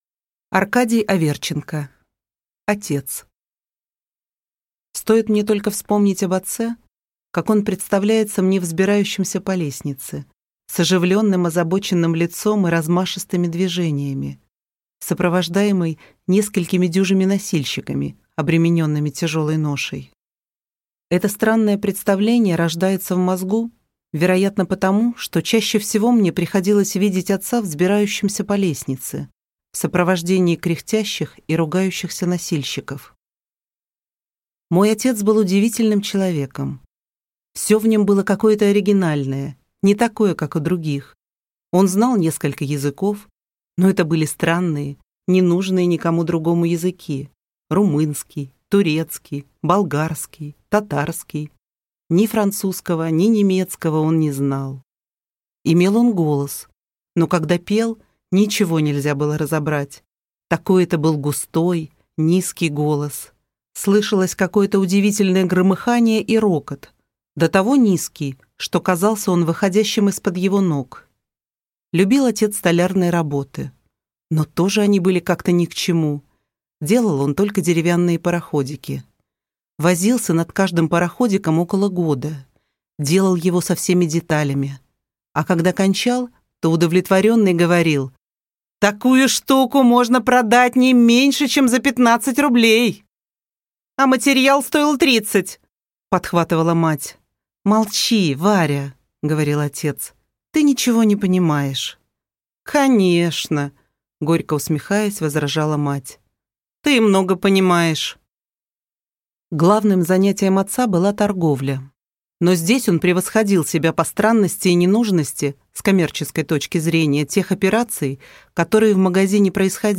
Аудиокнига Отец | Библиотека аудиокниг